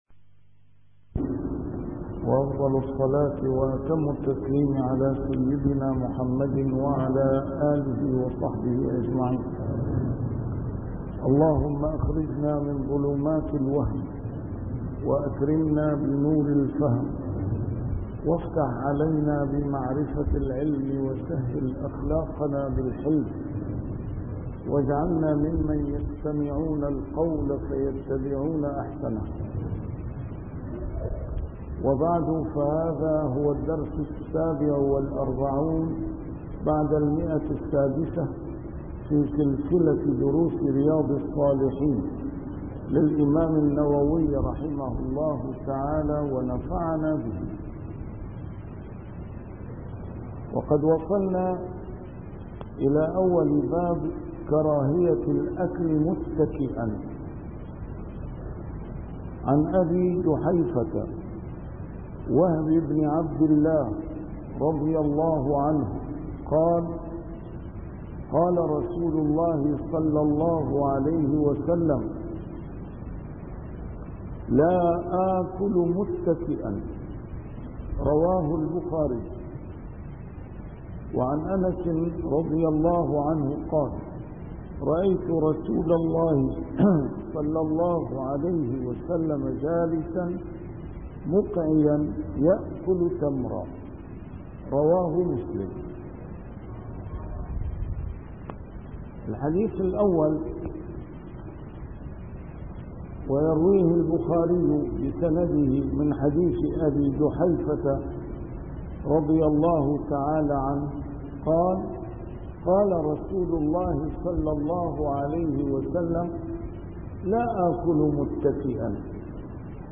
A MARTYR SCHOLAR: IMAM MUHAMMAD SAEED RAMADAN AL-BOUTI - الدروس العلمية - شرح كتاب رياض الصالحين - 647- شرح رياض الصالحين: كراهية الأكل متكئاً